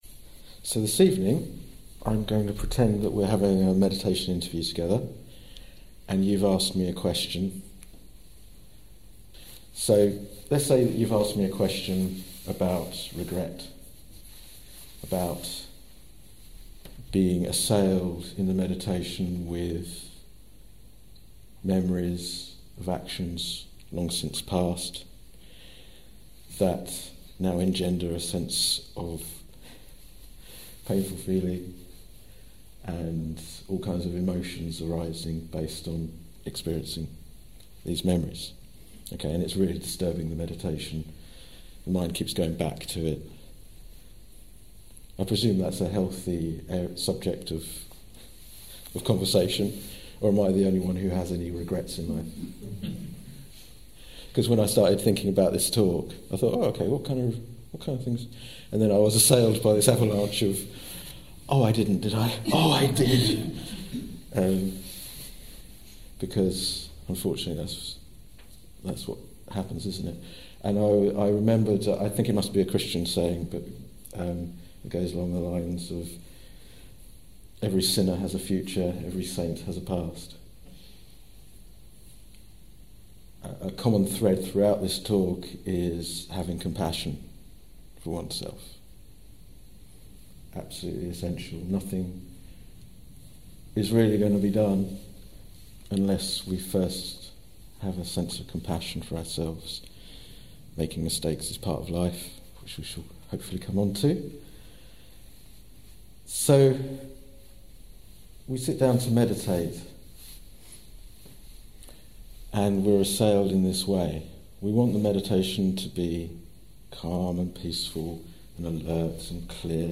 This talk was given in April 2017